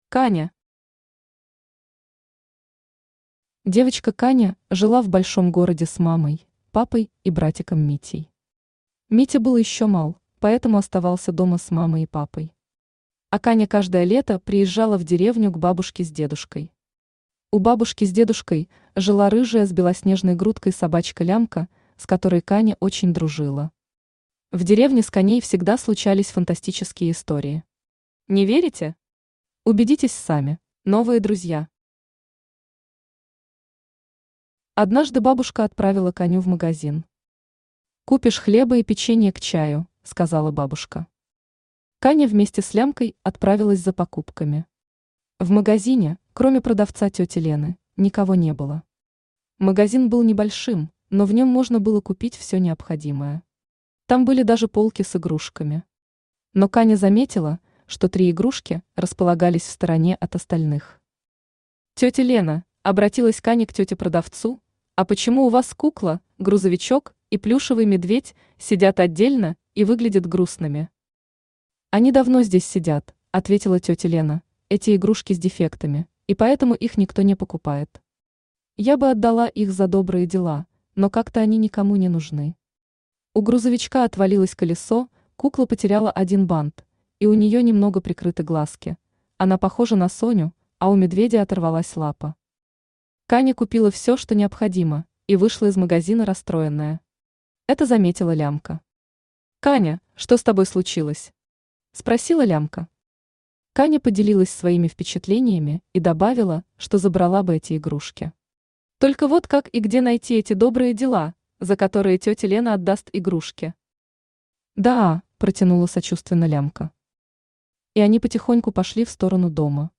Аудиокнига Канины истории | Библиотека аудиокниг
Aудиокнига Канины истории Автор Марина Капранова Читает аудиокнигу Авточтец ЛитРес.